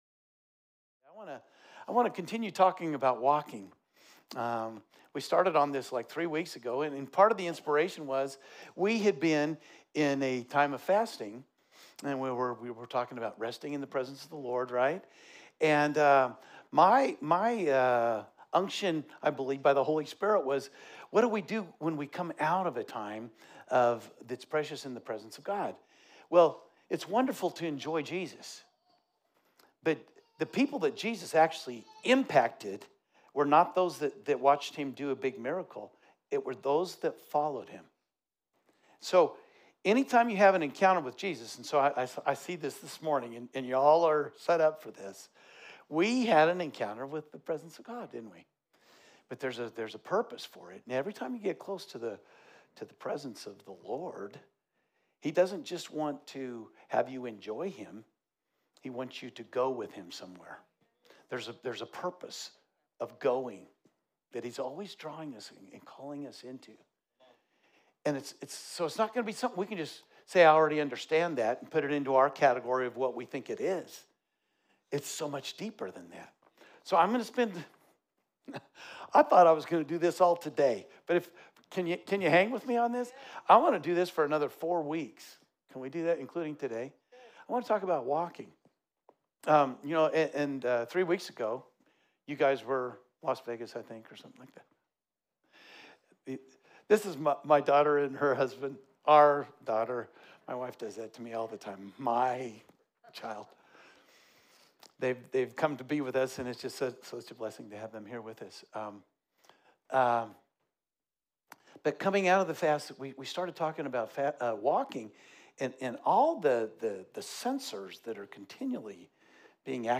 Sermons | New Life Church LH